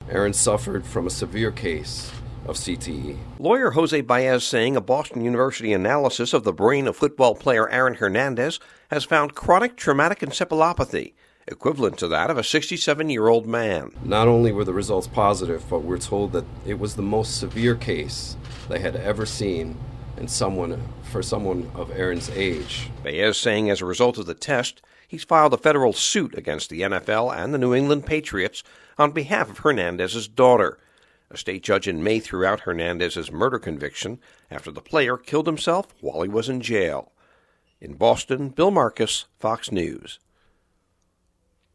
FOX NEWS RADIO’S